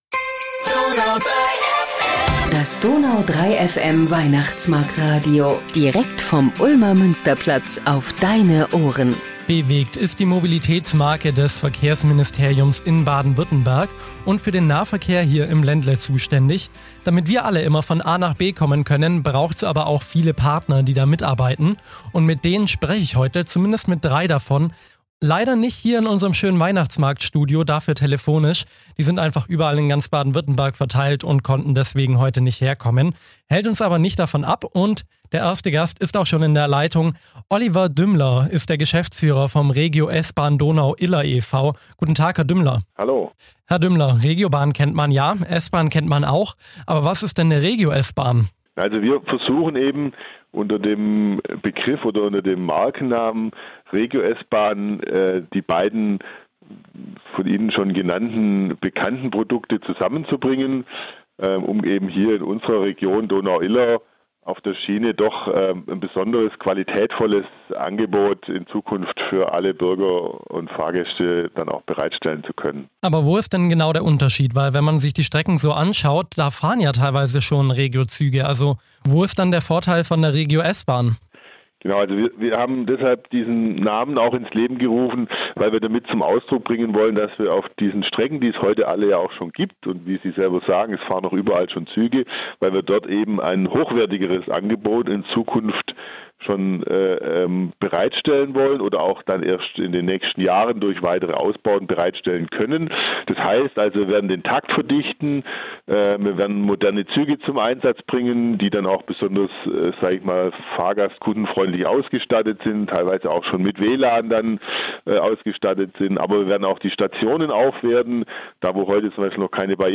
Beschreibung vor 2 Jahren bwegt ist die Mobilitätsmarke des Verkehrsministeriums Baden-Württemberg. Damit wir stets von A nach B kommen, arbeitet bwegt mit vielen Partnern in ganz Baden-Württemberg zusammen. In dieser Folge spricht DONAU 3 FM Reporter